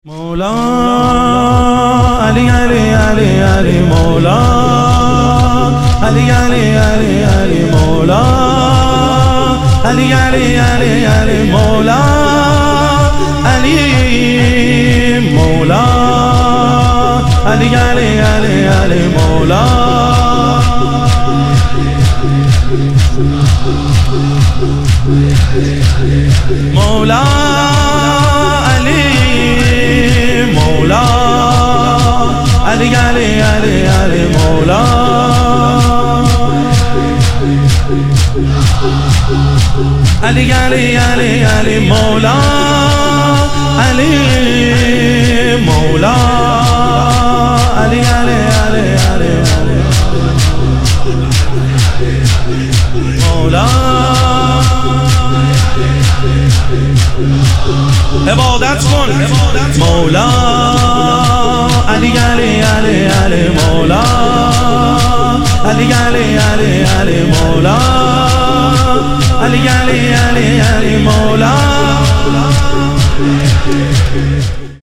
محرم الحرام - شور